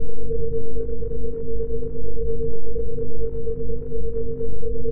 AMBIENCE_SciFi_Static_Tonal_loop_stereo.wav